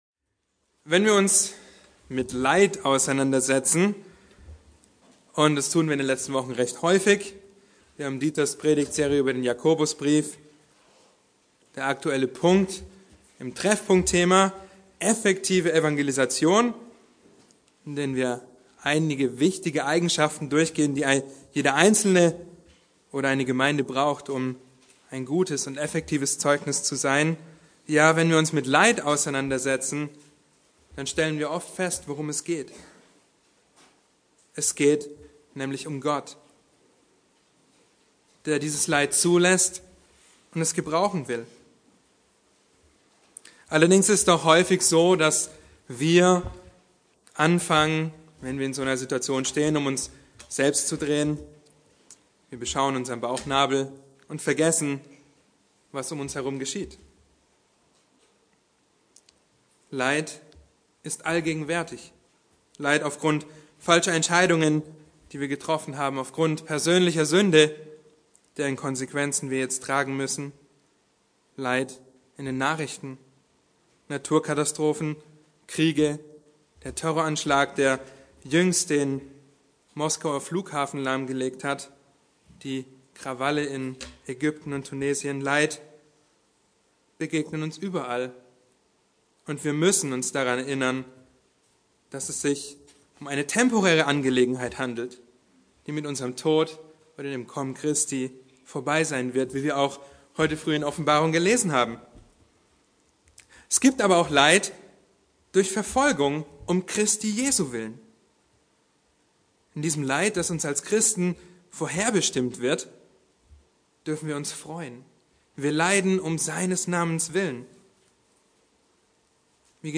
Bibelstunden - Bibelgemeinde Barnim